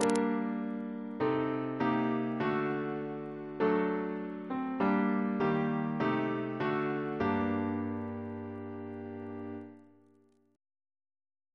Single chant in G minor Composer: Edwin George Monk (1819-1900), Organist of York Minster Reference psalters: ACB: 31; ACP: 3; OCB: 284